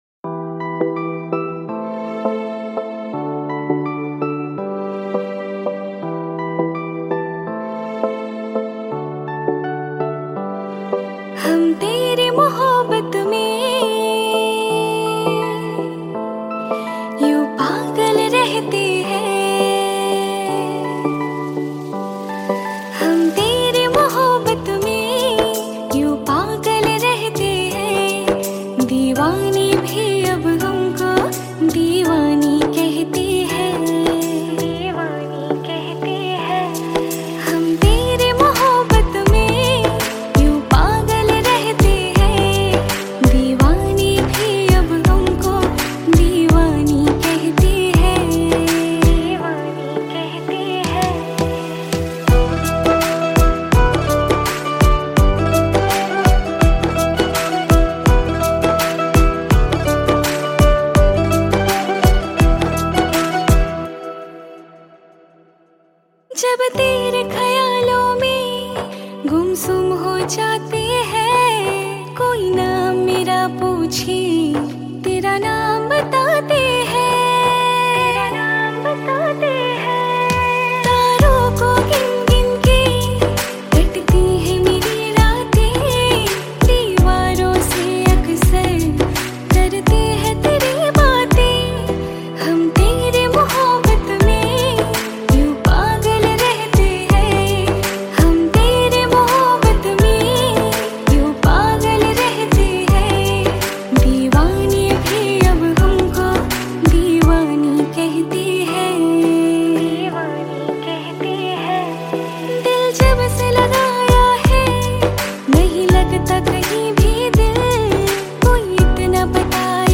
Bollywood Cover Songs